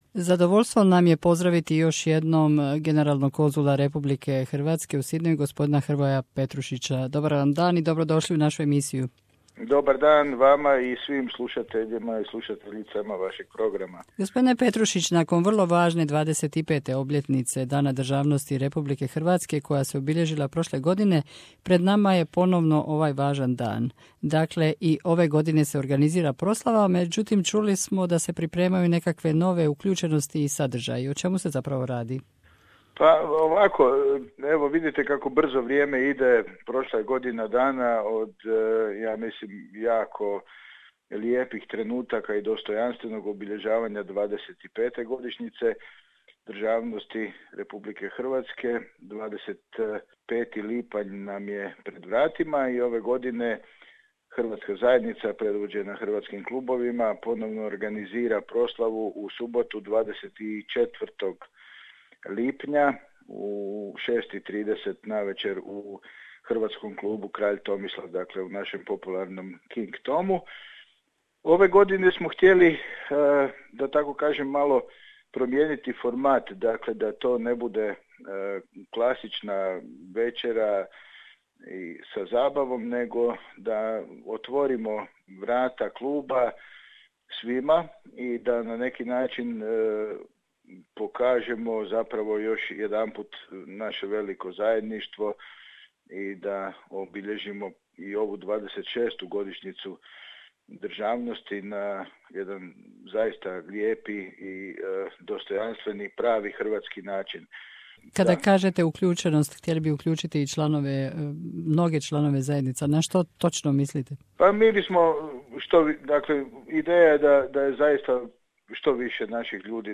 Ujedinjeni hrvatski klubovi u NSW i Hrvatsko australsko vijeće u suradnji s Generalnim konzulatom Republike Hrvatske u Sydneyu se pripremaju za obilježavanje Dana državnosti Republike Hrvatske u Sydney o čemu nam govori Generali konzul Republike Hrvatske u Sydneyu Hrvoje Petrušić.